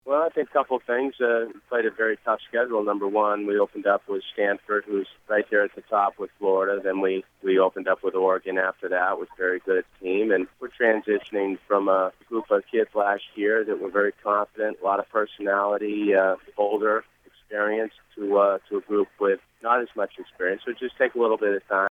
Commodores head coach Tim Corbin says there are a few reasons for his team’s less than stellar start.